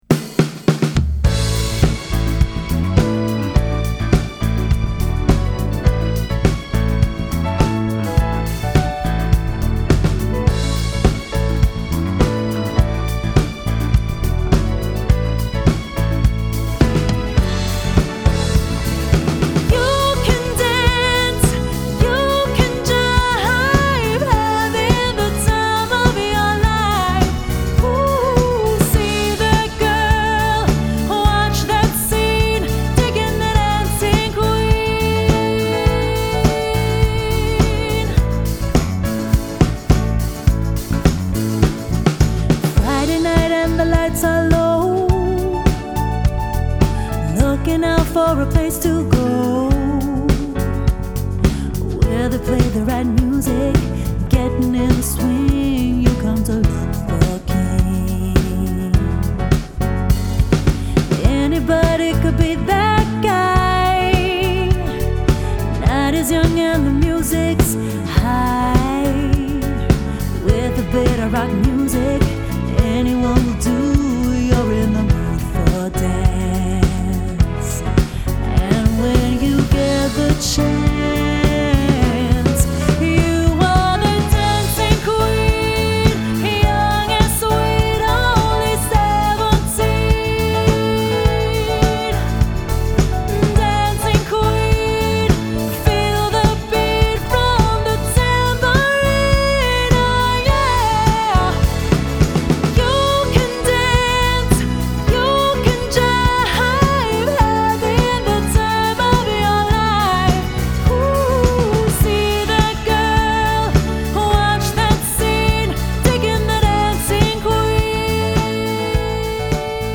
Quintett
Exzellente Livemusik für ihre Party.